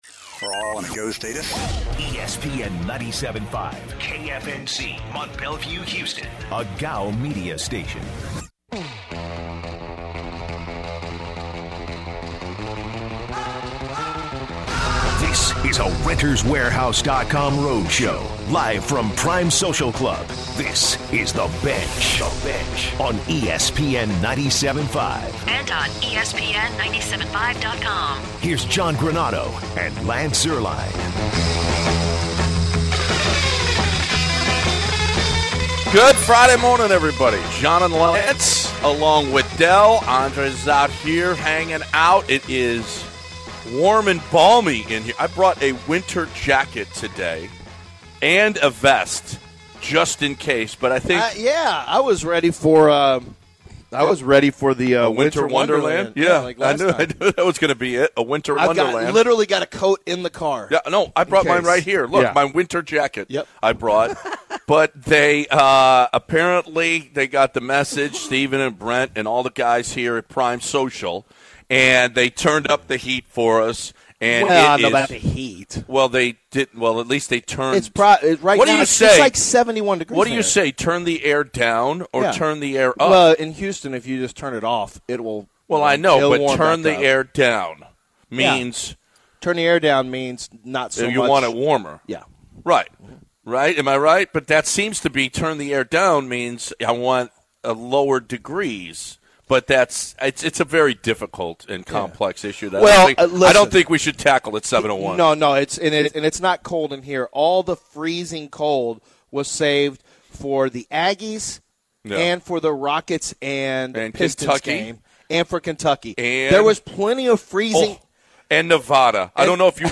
The guys were live at the Prime Social Club today. They go into some bad basketball played in the Sweet 16 NCAA basketball tournament and by your home town Rockets, the Rockets in a win.